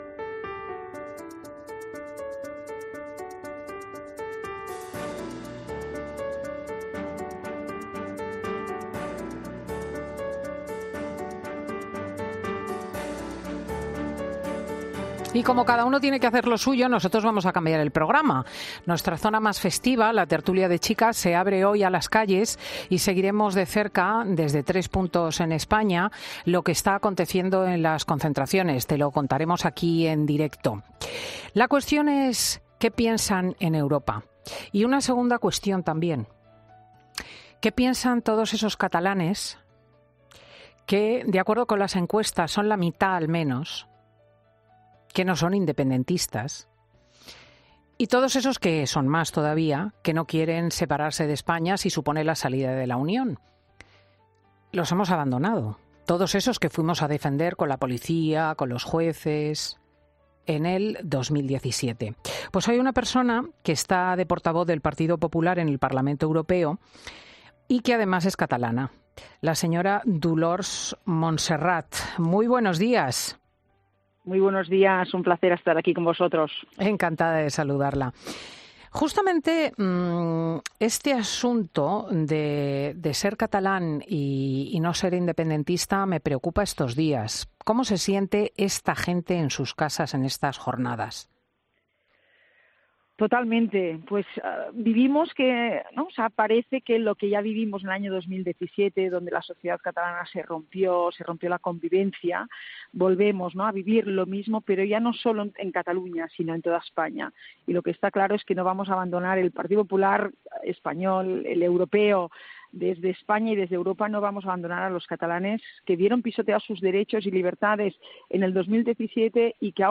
La portavoz del PP en el Parlamento Europeo expresaba en 'Fin de Semana' cómo ven desde Europa los pactos de Sánchez con los independentistas y su...